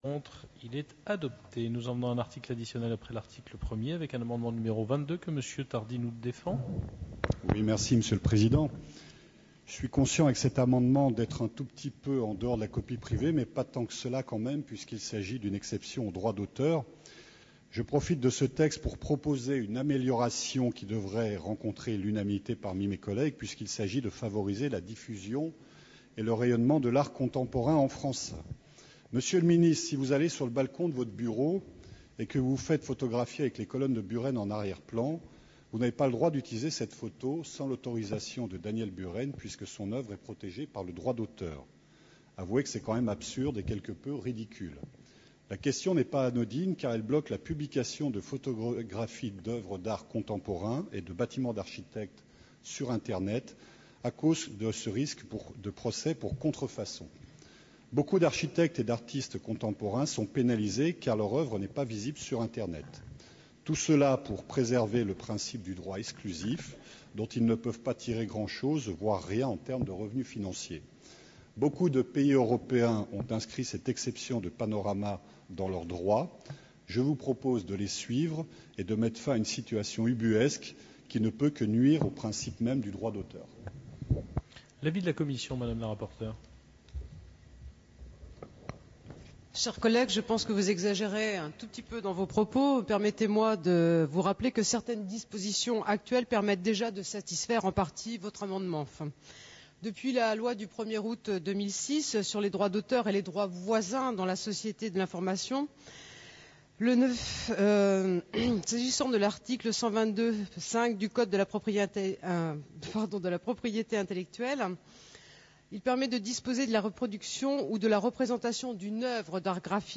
Débats de l'Assemblée nationale sur l'amendement 22 « panoramas » au projet de loi sur la copie privée (23/11/11)